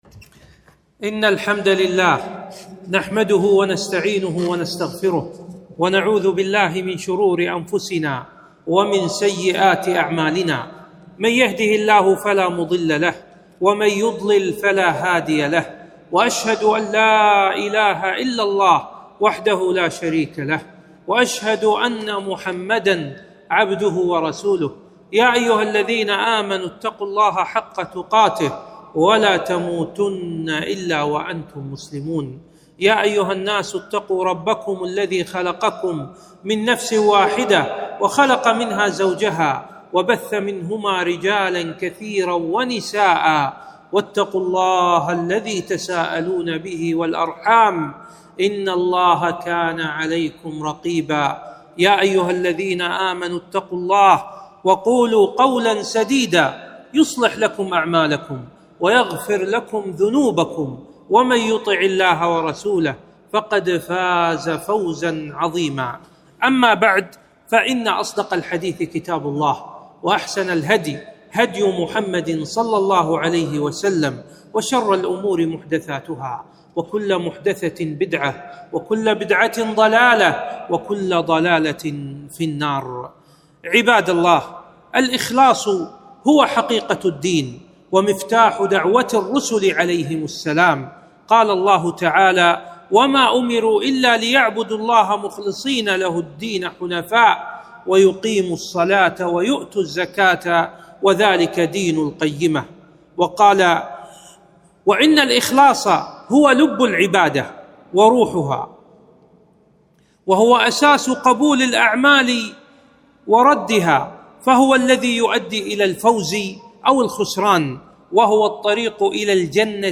خطبة - الإخلاص